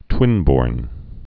(twĭnbôrn)